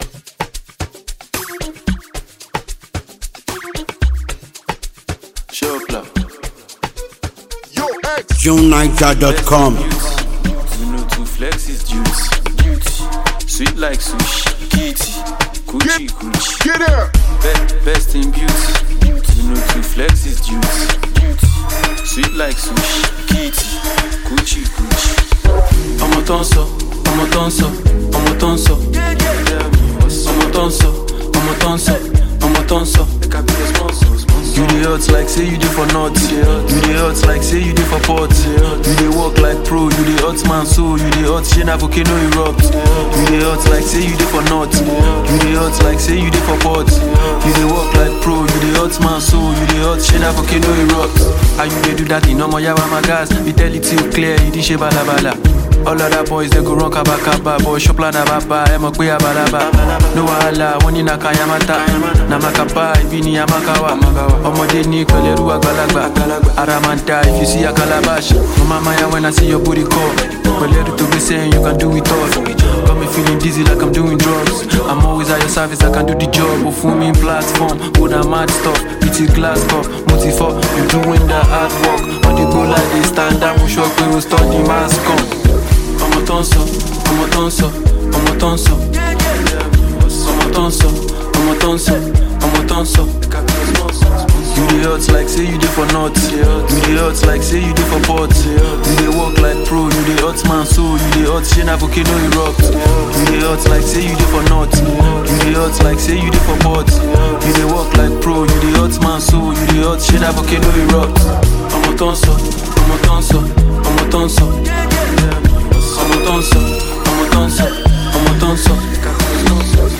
a phenomenally talented Nigerian rapper and songwriter